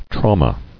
[trau·ma]